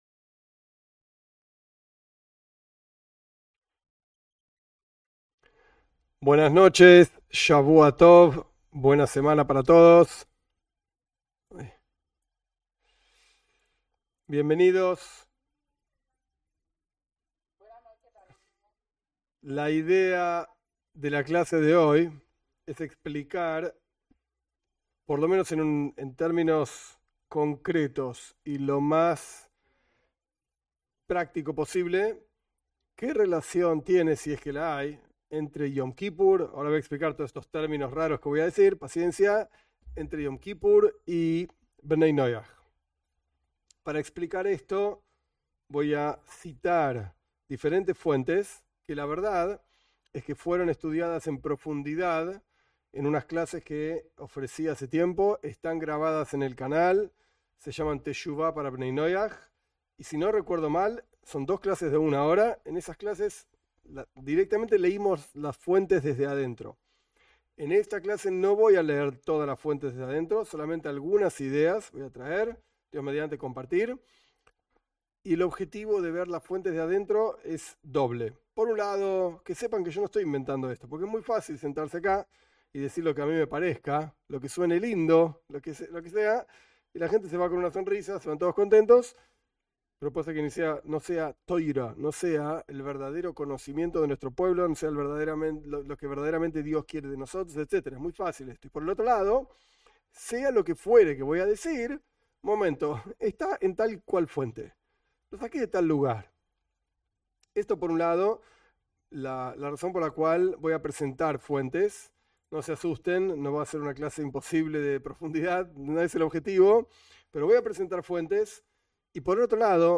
En esta clase se explica qué relación tienen los no judíos con la fiesta judía de Iom Kipur. Se ofrece una guía para saber qué hacer, qué no hacer y por qué.